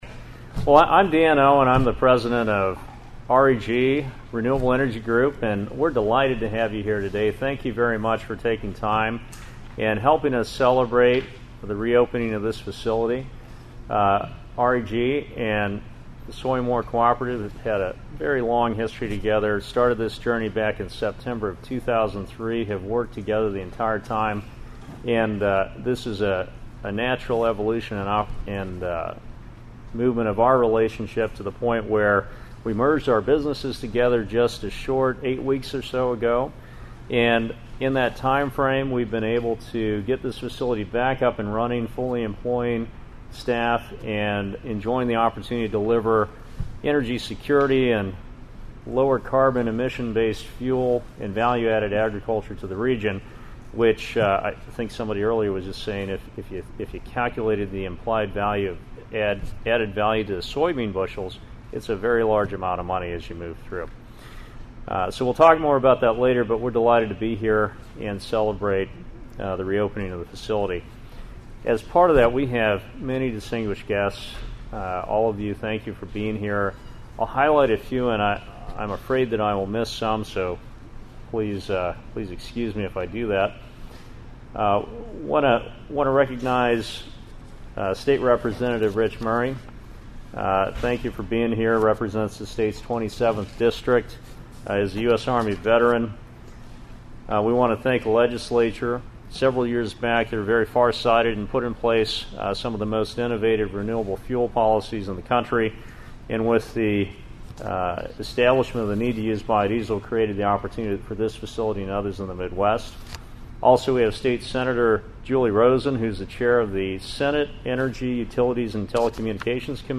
Listen to the opening ceremony comments here.